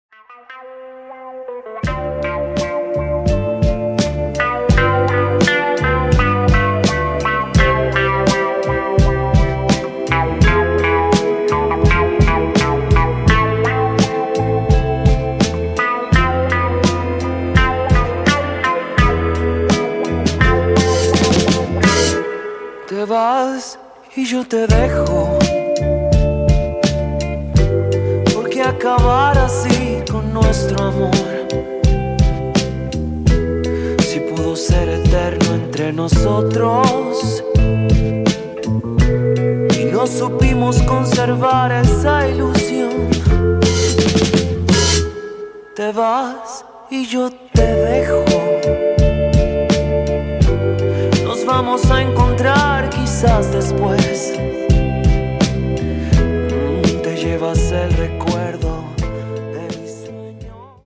Psychedelic Latin Soul